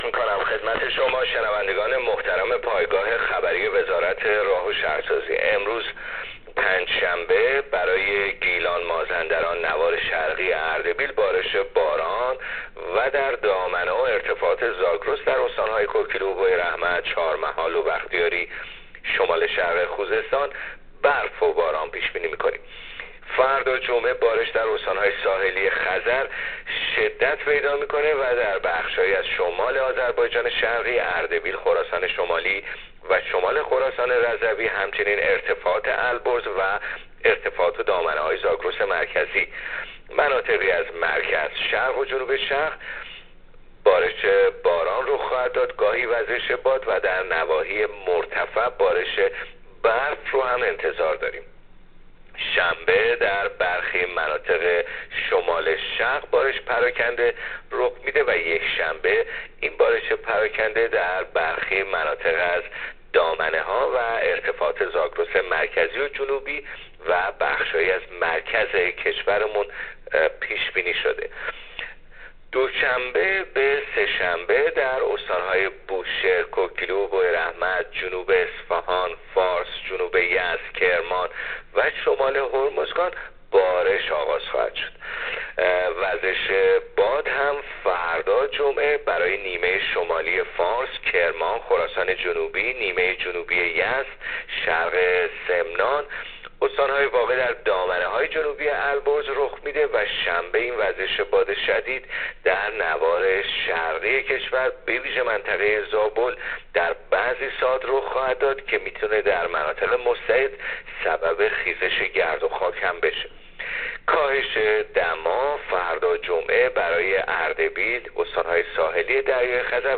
گزارش رادیو اینترنتی پایگاه‌خبری از آخرین وضعیت آب‌وهوای چهارم اسفند؛